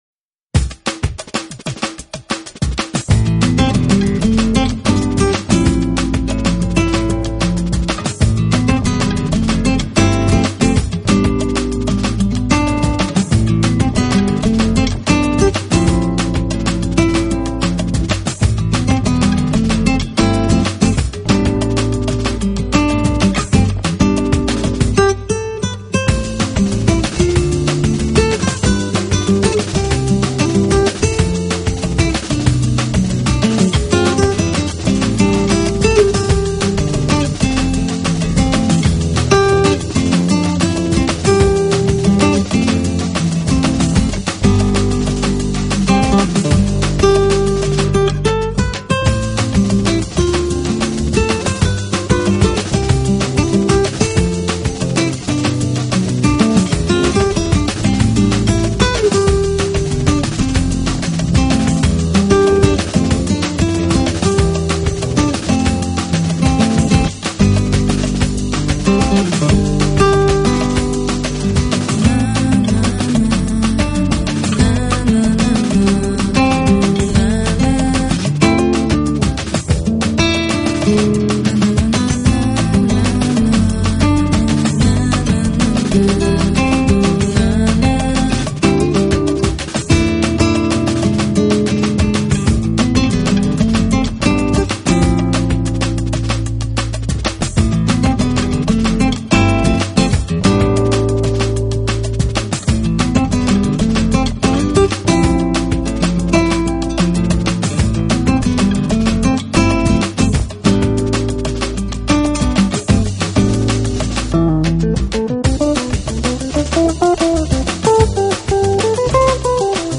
音乐类型: Smooth Jazz / Jazz